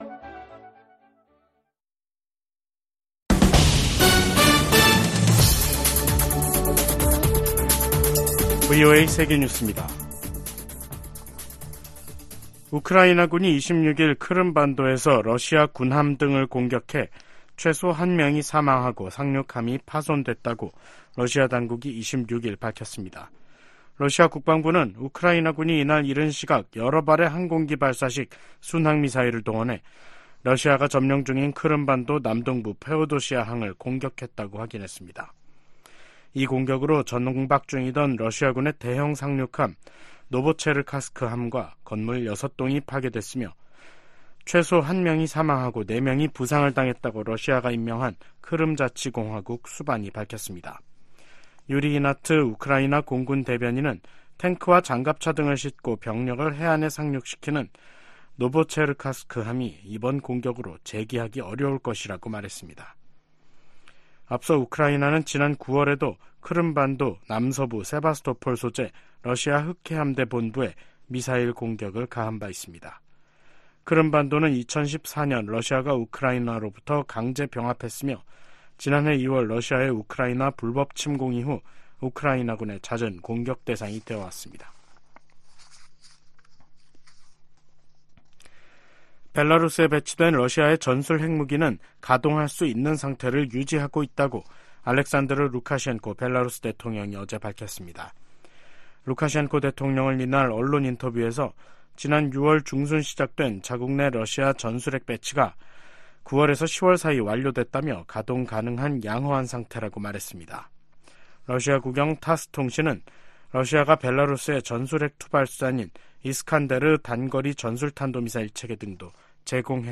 VOA 한국어 간판 뉴스 프로그램 '뉴스 투데이', 2023년 12월 26일 3부 방송입니다. 조 바이든 미 대통령이 서명한 2024회계연도 국방수권법안에는 주한미군 규모를 현 수준으로 유지하는 내용과 한반도 관련 새 조항들이 담겼습니다. 북한이 영변의 실험용 경수로를 완공해 시운전에 들어간 정황이 공개되면서 한국 정부는 동향을 예의주시하고 있습니다. 유엔난민기구가 중국 정부에 탈북민의 열악한 인권 실태를 인정하고 개선할 것을 권고했습니다.